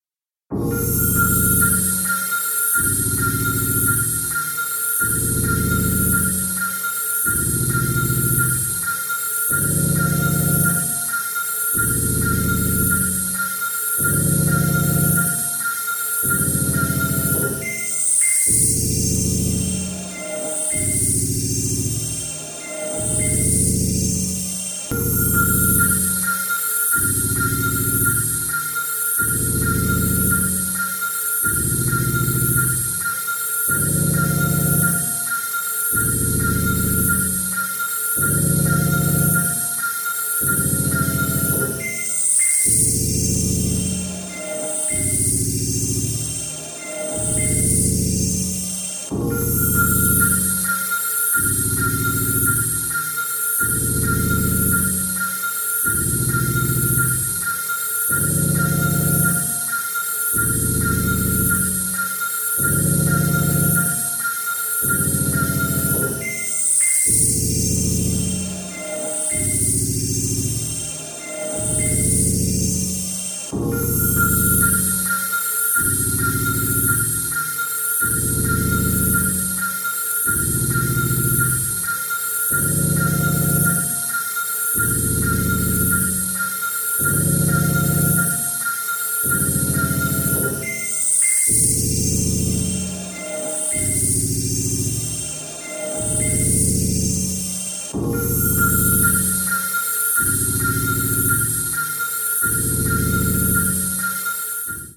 丁寧に音の重ねて深遠な世界を描いてます！